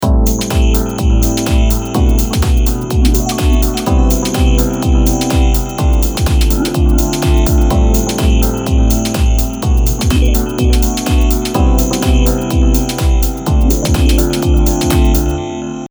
これにドラムを加えて聞いてみましょう。
ドラムとピアノの2パートだけですが、それ以上の存在感を与えてくれる感じがしますね。